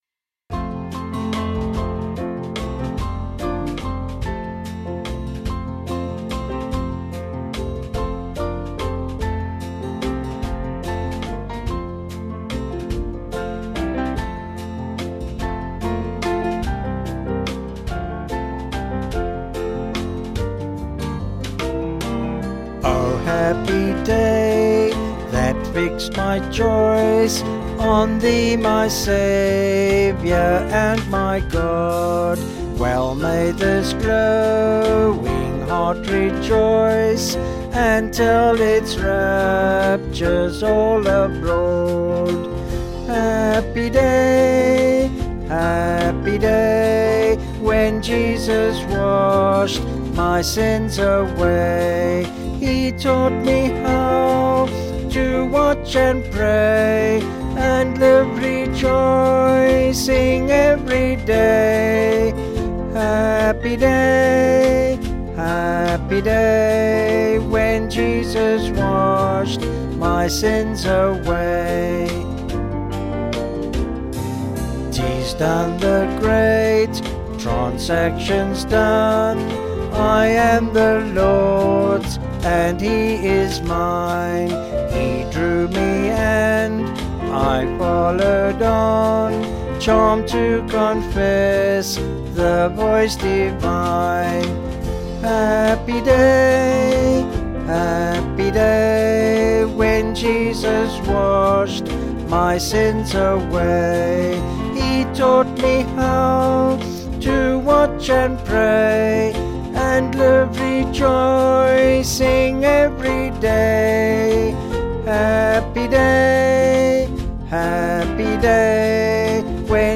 Vocals and Band   264.2kb Sung Lyrics